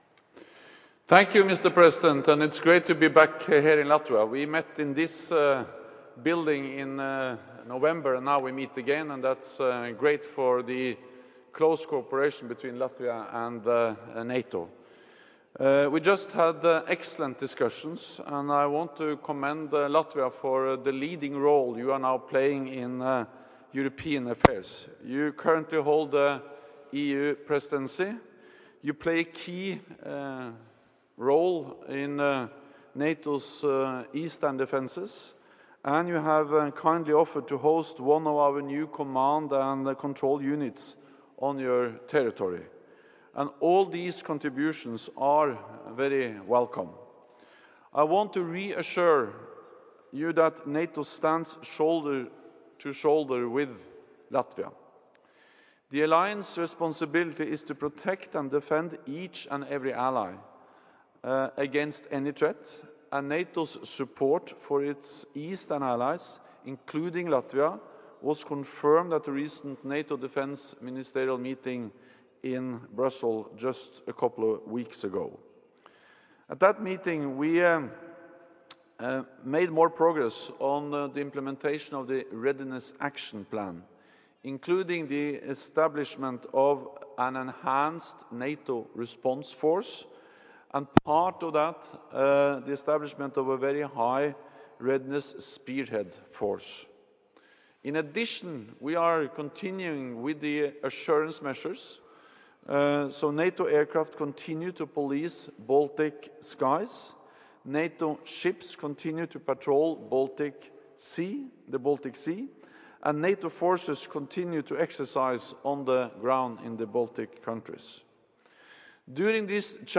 Opening remarks
by NATO Secretary General Jens Stoltenberg at the joint press point with the Latvian President